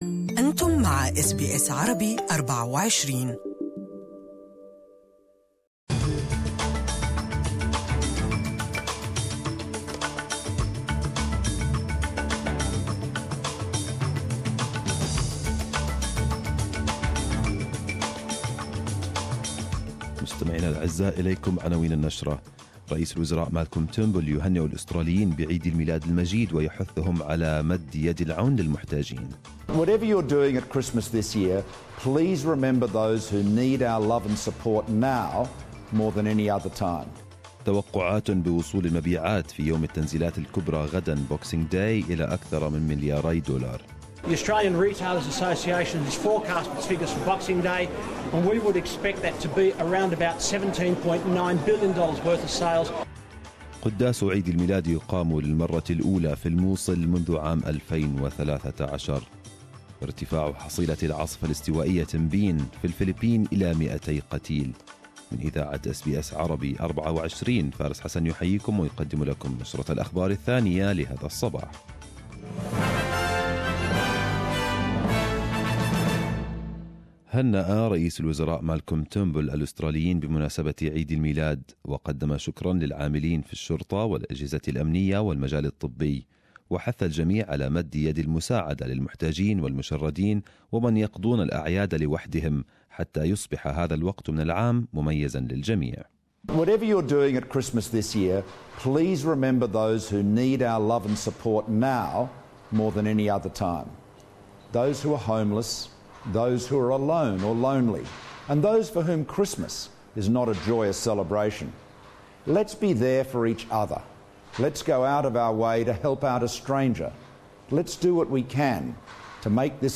News of 25th of December including the greetings of PM Turnbull and opposition leader Bill Shorten.